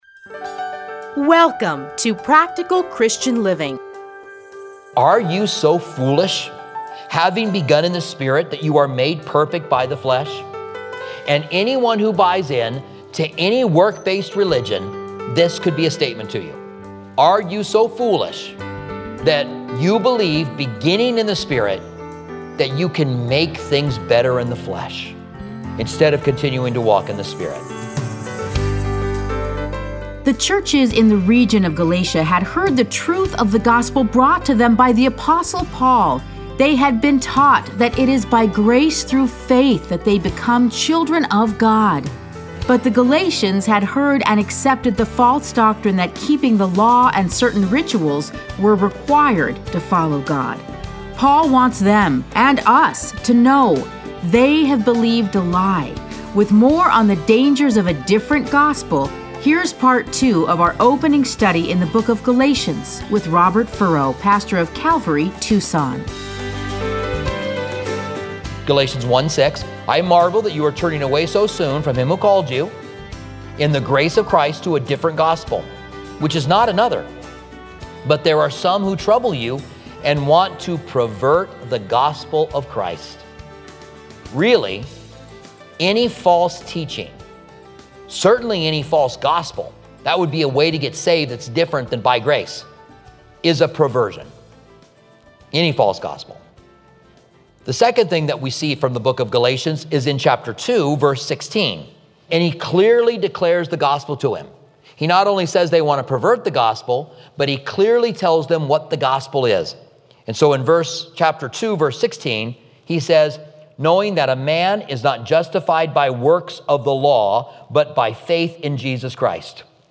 Listen to a teaching from Galatians 1:1-6.